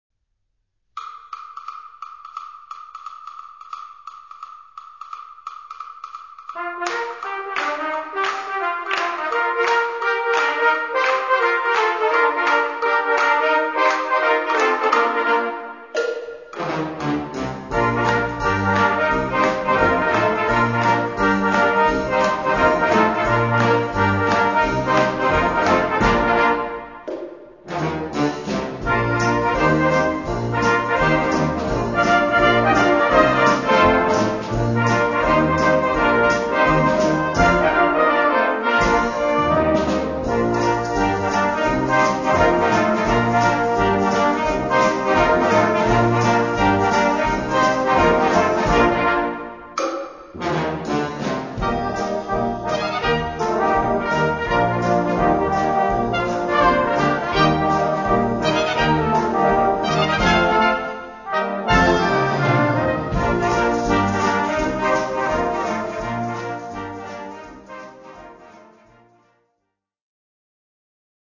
Gattung: Top Evergreen
Besetzung: Blasorchester